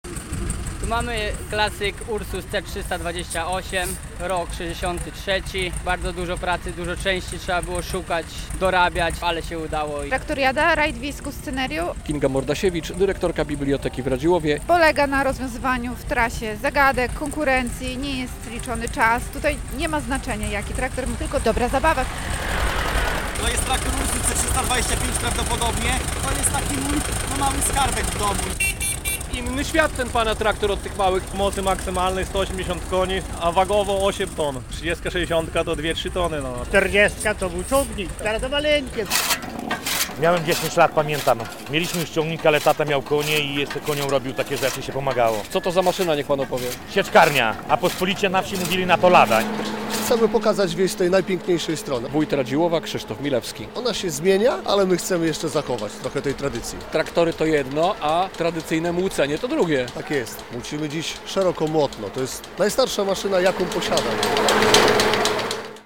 Traktoriada w Radziłowie - relacja